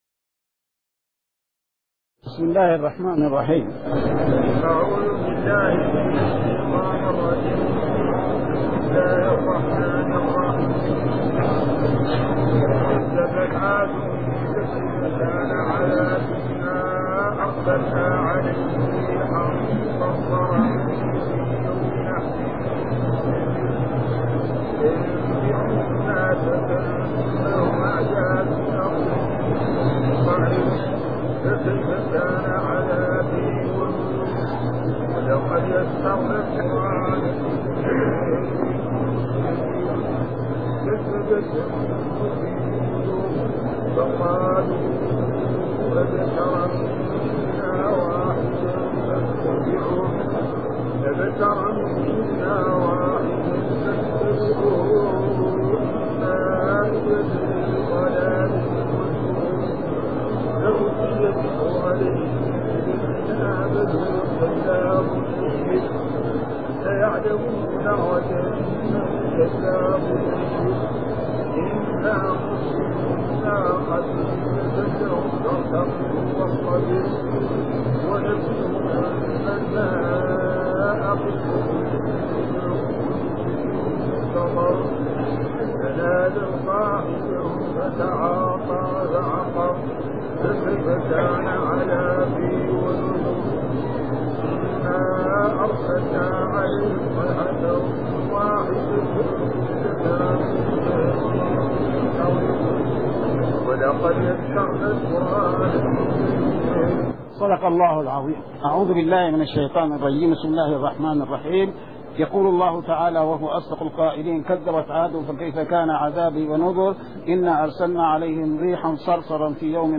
من دروس الحرم المدنى الشريف تفسير الآية 1-17من سورة القمر